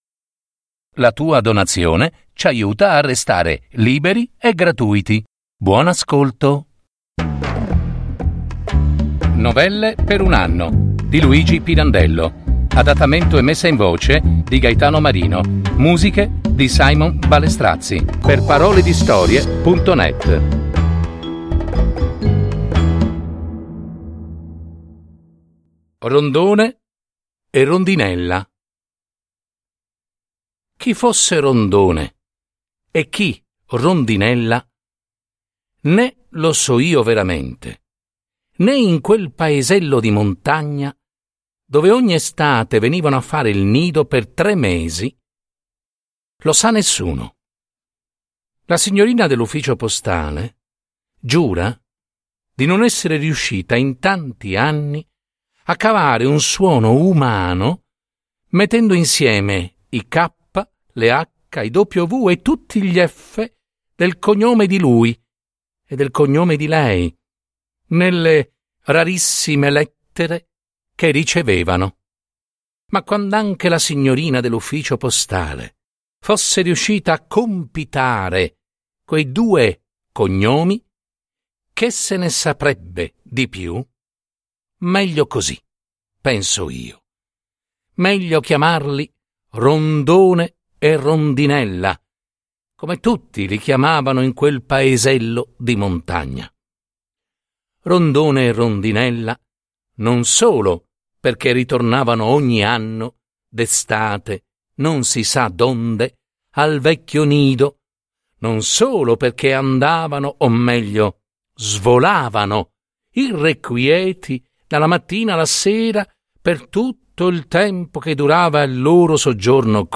Messa in voce